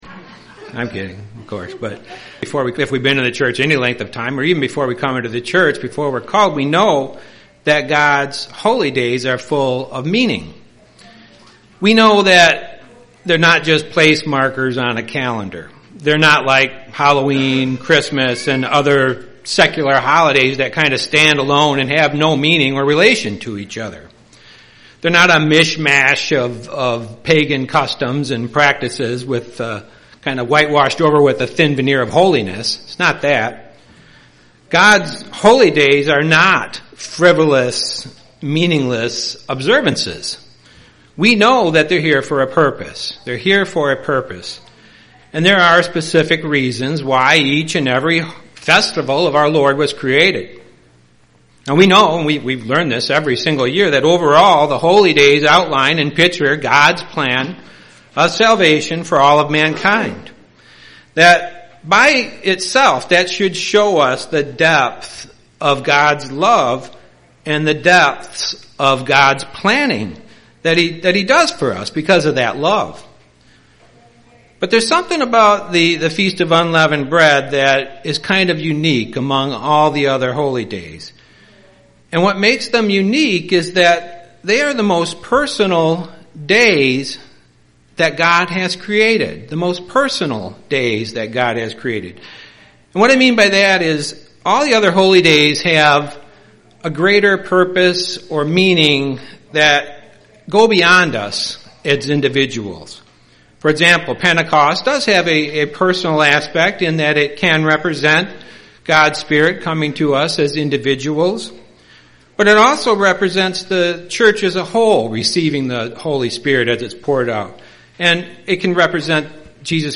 Sermons
Given in Grand Rapids, MI Kalamazoo, MI Lansing, MI